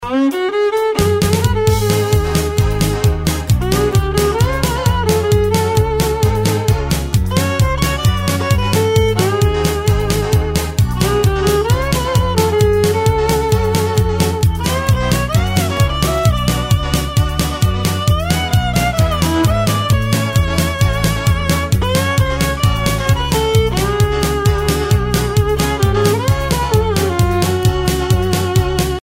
Рингтоны » Шансон